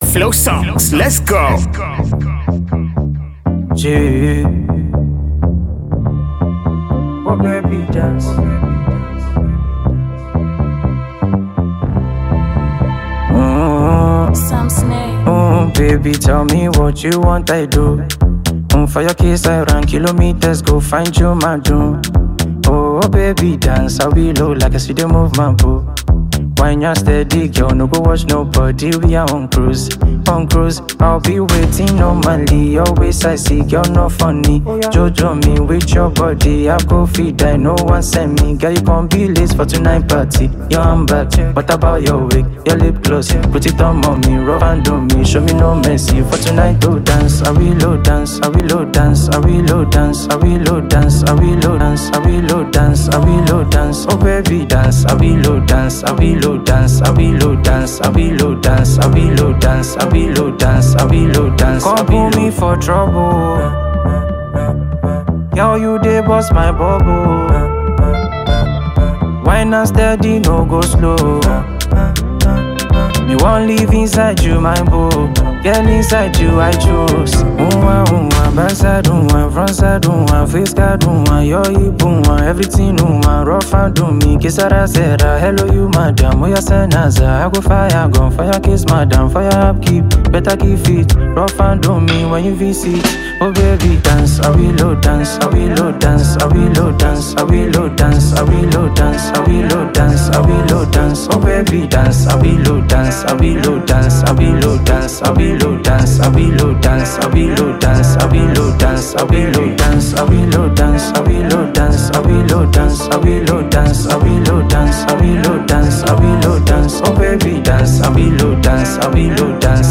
It is a catchy and lively melody.
Highlife and Afrobeat